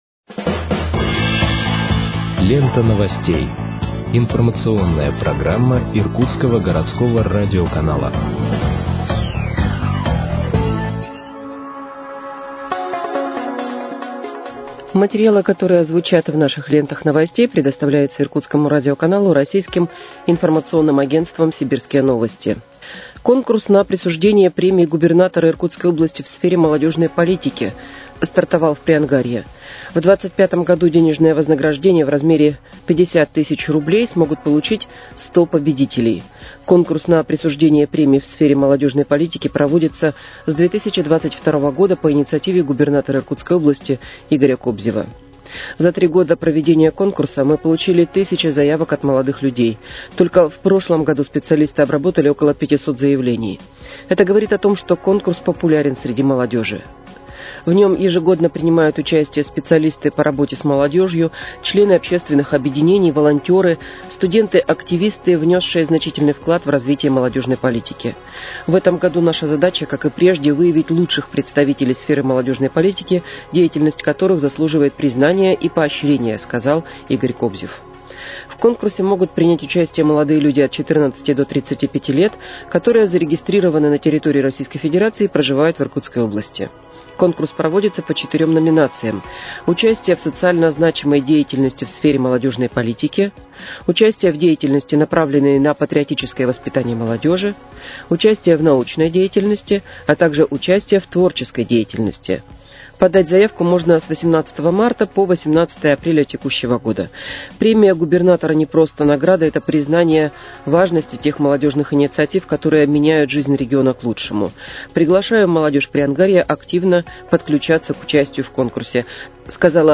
Выпуск новостей в подкастах газеты «Иркутск» от 18.03.2025 № 1